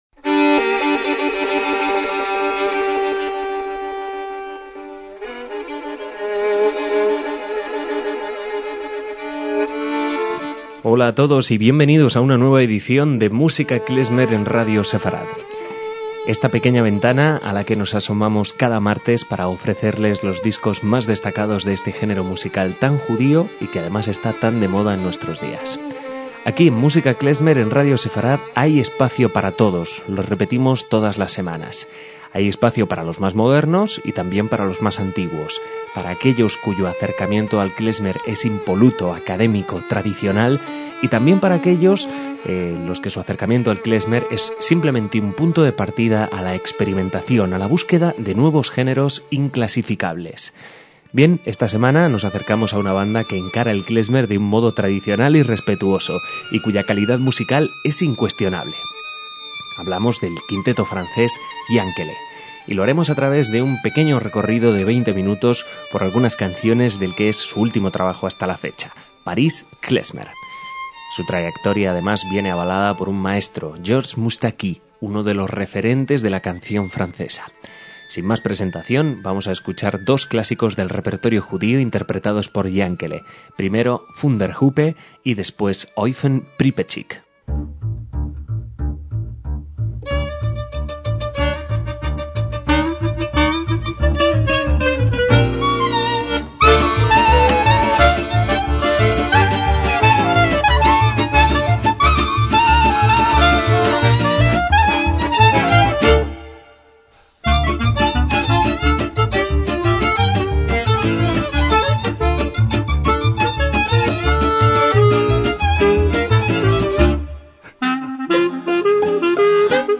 MÚSICA KLEZMER
valses franceses, canciones gitanas, nanas israelíes